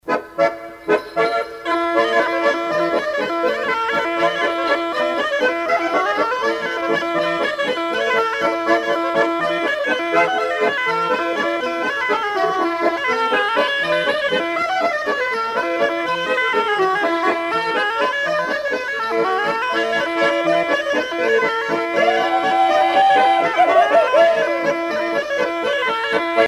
Pot-pourri de bourrées
danse : bourree
Pièce musicale éditée